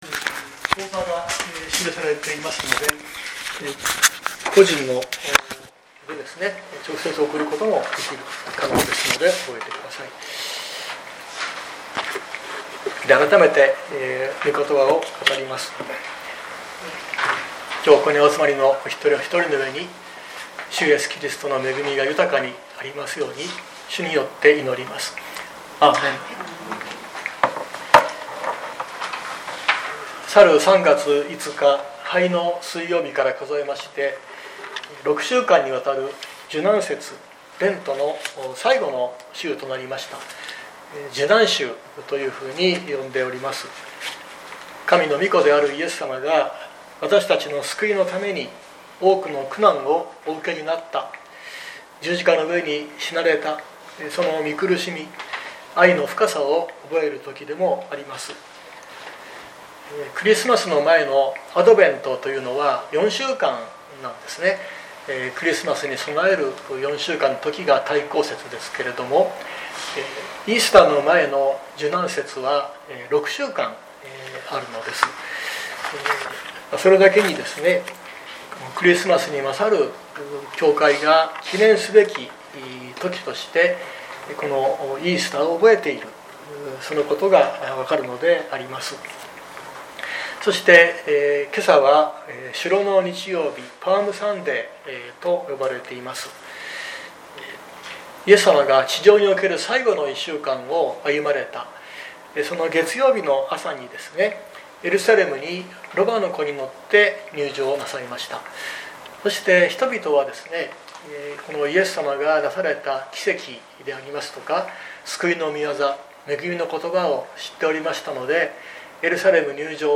2025年04月13日朝の礼拝「コルネリウスの洗礼」熊本教会
熊本教会。説教アーカイブ。